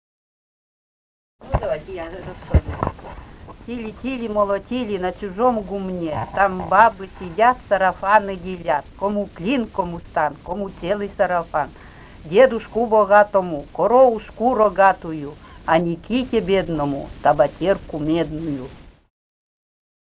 «Тили, тили, молотили» («соберуха»).